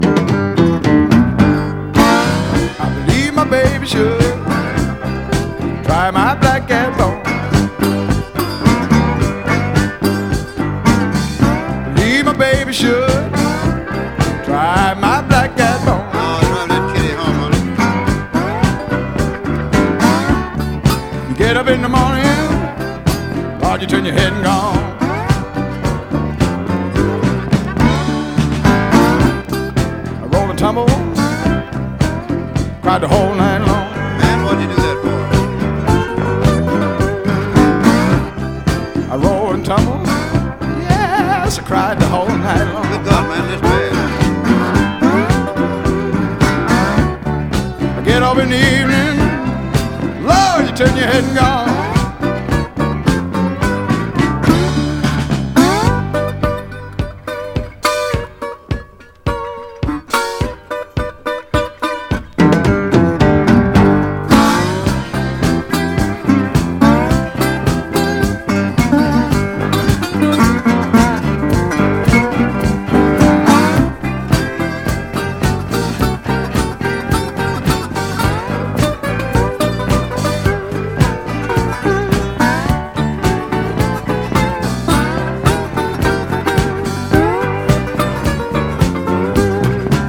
BREAKBEATS/HOUSE / BRAZILIAN HOUSE / 90'S (JPN)
爽快ブラジリアン・ハウス
サウダージ誘うギターが切ない素晴らしいチルアウト・ダブとなっております。